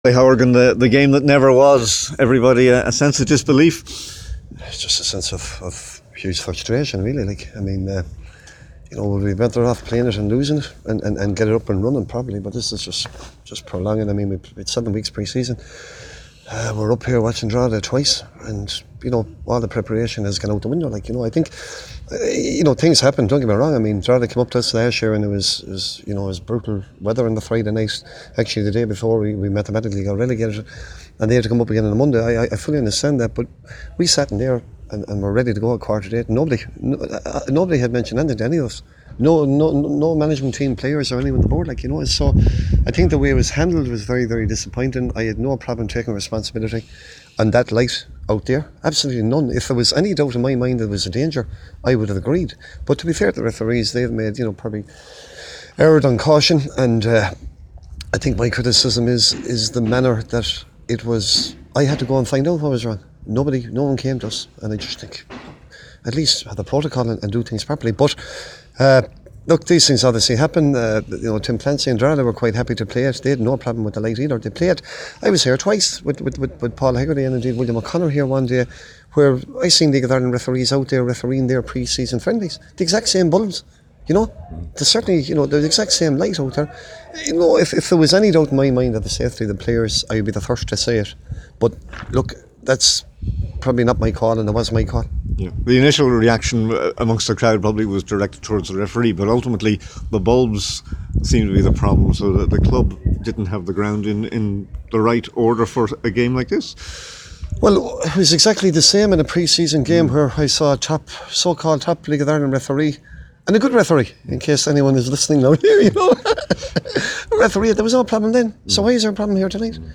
in Drogheda